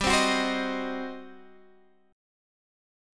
GM7sus4#5 chord